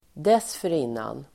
Uttal: [²desför'in:an]